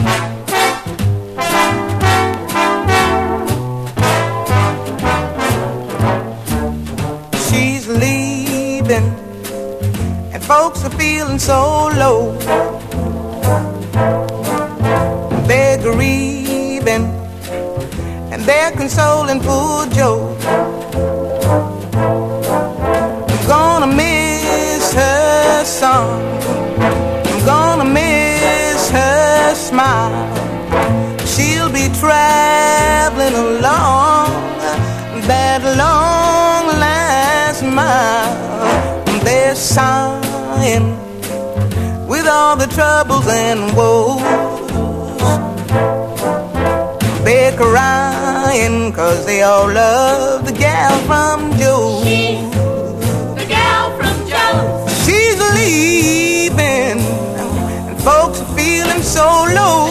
スウィンギンなギターが最高な高速ジャイヴ・コーラス